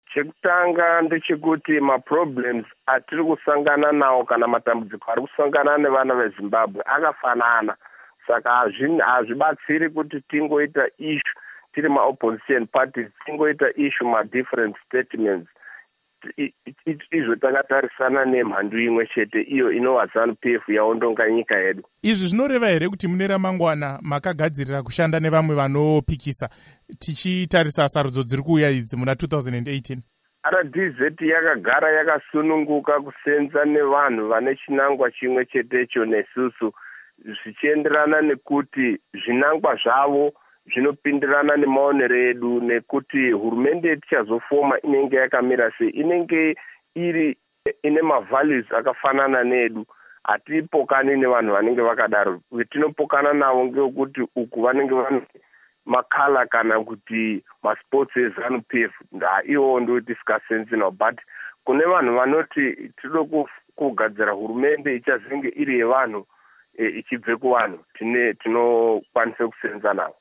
Hurukuro naVaPishai Muchauraya